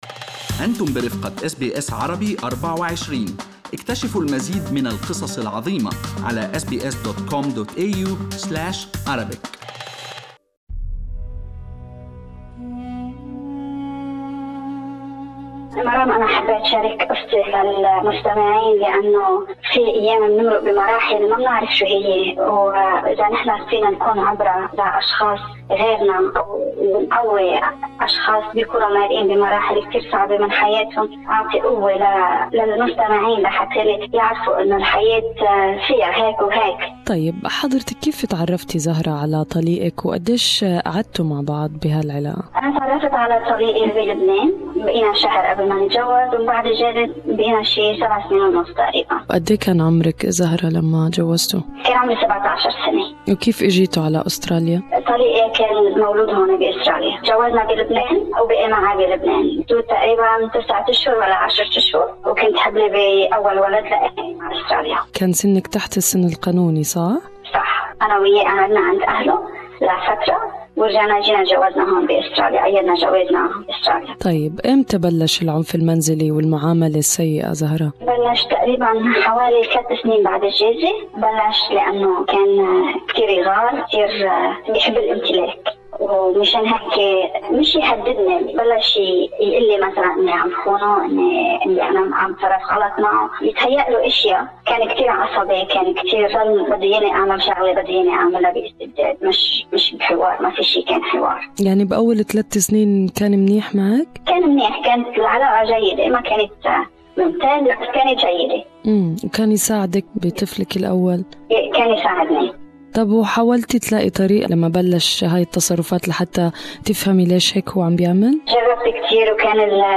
سيدة عربية تروي قصتها مع العنف المنزلي لتعطي الأمل للنساء المعنفات